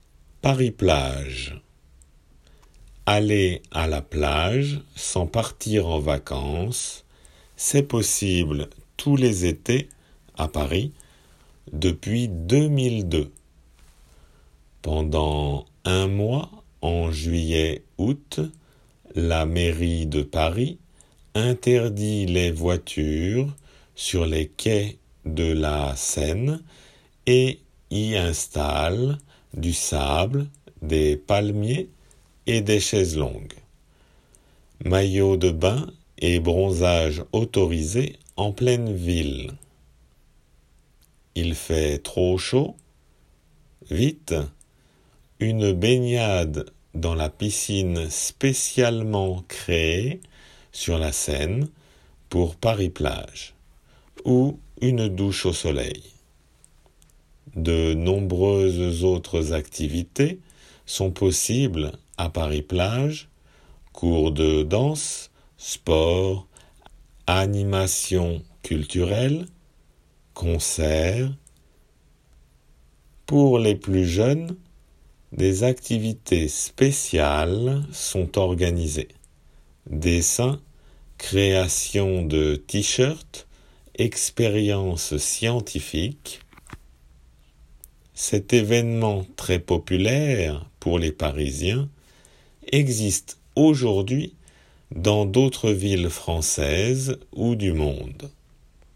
仏検受験用　聞き取り正誤問題－音声